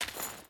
Footsteps / Dirt
Dirt Chain Walk 2.ogg